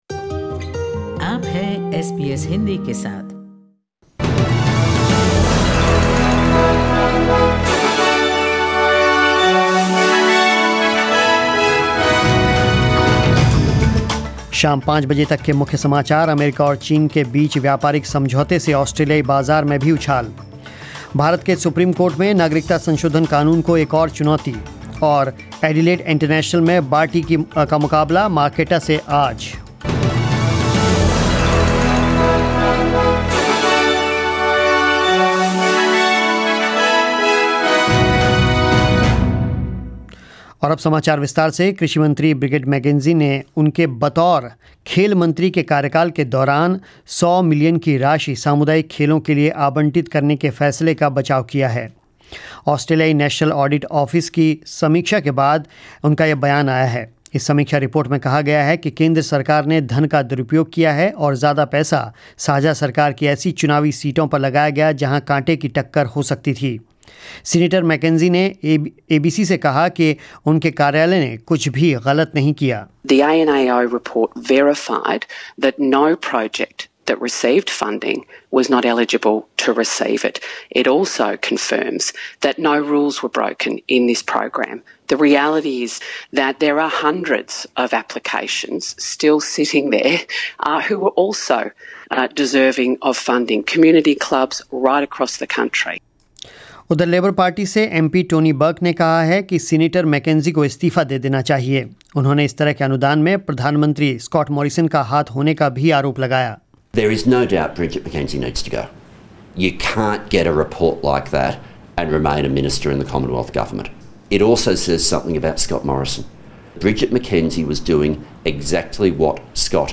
News in Hindi